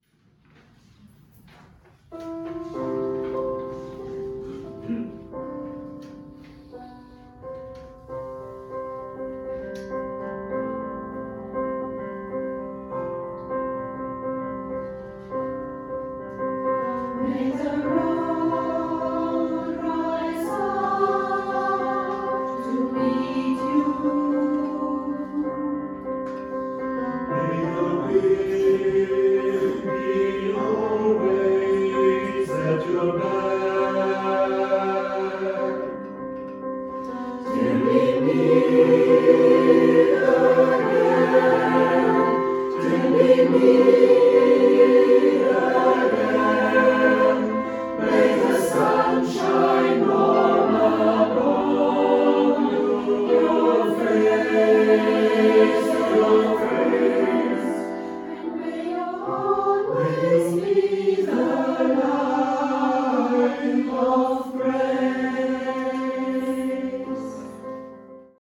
Herzlich Willkommen beim Gospelchor
Die Lieder wurden während unserer Probe mit einem Handy aufgenommen und haben keine Studioqualität.